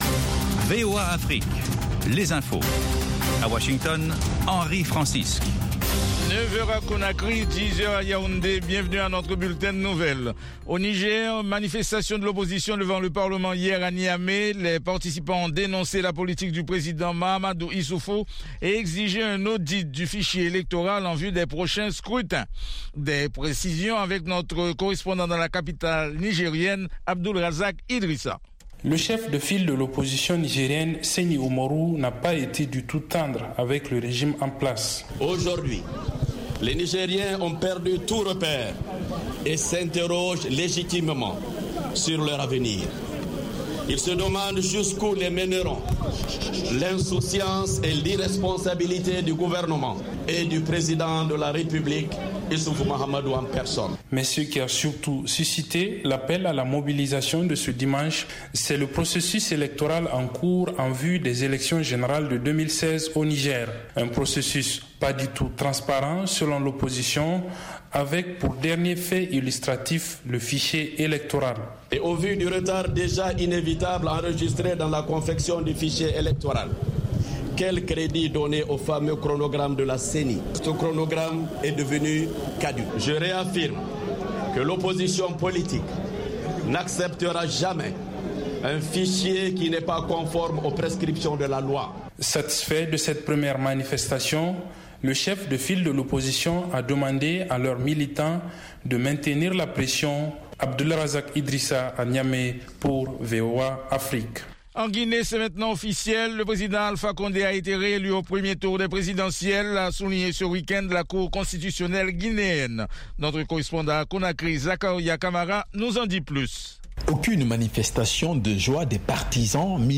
Bulletin
5 min News French